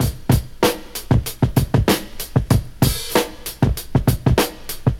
• 96 Bpm Breakbeat Sample C Key.wav
Free drum groove - kick tuned to the C note. Loudest frequency: 1388Hz
96-bpm-breakbeat-sample-c-key-xYY.wav